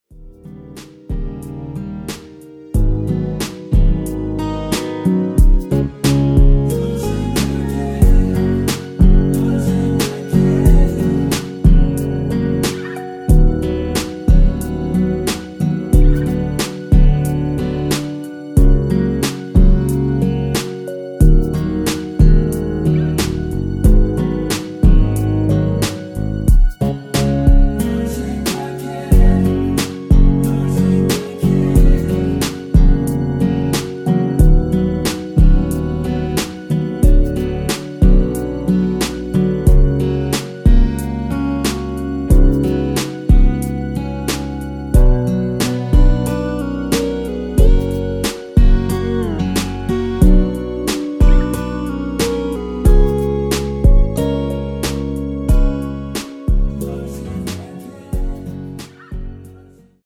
원키에서 (-1)내린 코러스 포함된 MR 이며 아래의 가사에 노란색 부분을참조 하세요~
F#
◈ 곡명 옆 (-1)은 반음 내림, (+1)은 반음 올림 입니다.
앞부분30초, 뒷부분30초씩 편집해서 올려 드리고 있습니다.